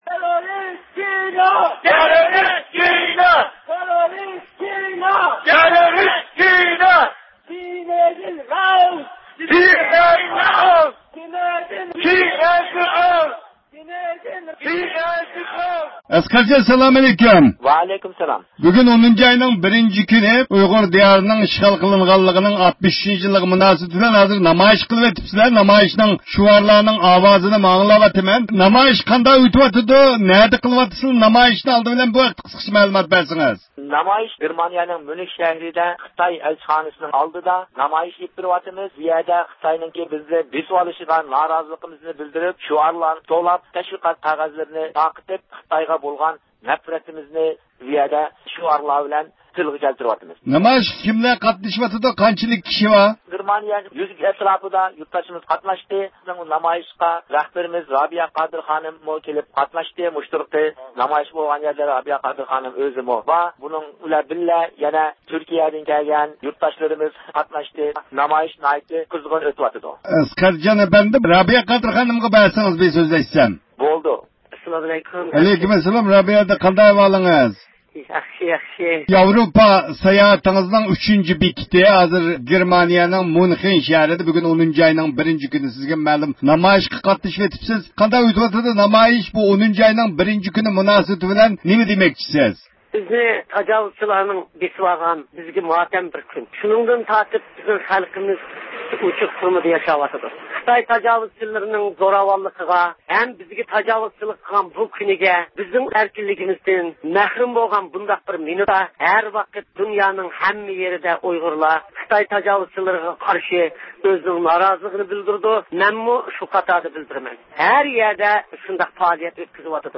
بىز نەق مەيدانغا تېلېفون قىلىپ، دۇنيا ئۇيغۇر قۇرۇلتىيى رەئىسى رابىيە قادىر خانىم ۋە باشقا رەھبەرلەر بىلەن تېلېفون سۆھبىتى ئېلىپ باردۇق.